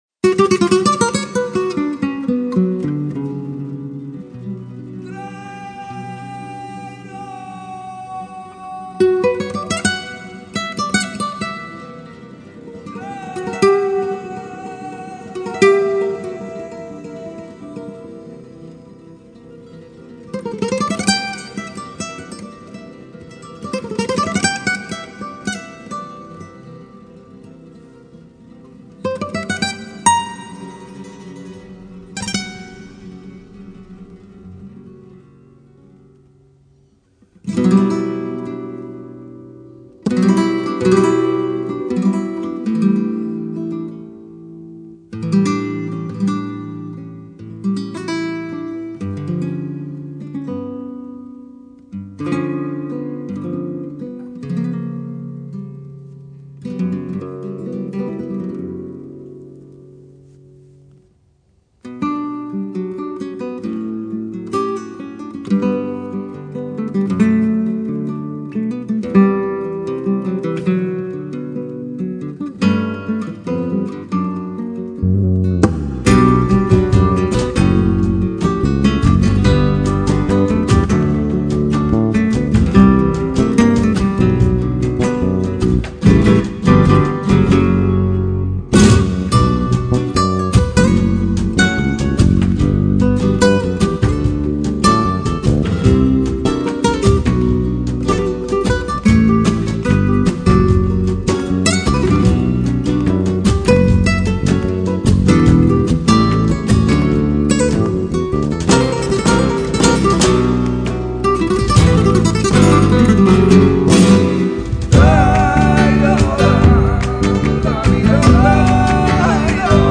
フラメンコのＣＤを買いに行きました。